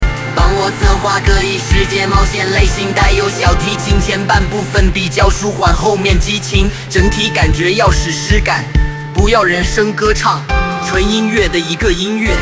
帮我策划个异世界冒险类型带有小提琴前半部分比较舒缓后面激情，整体感觉要史诗感，不要人声歌唱，纯音乐的一个音乐